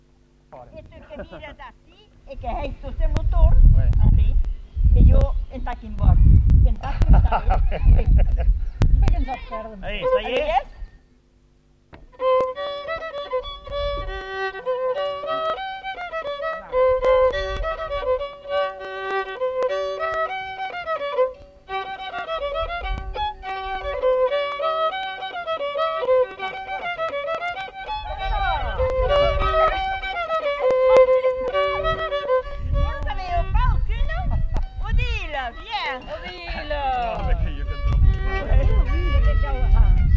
Aire culturelle : Petites-Landes
Lieu : Labrit
Genre : morceau instrumental
Instrument de musique : violon
Danse : congo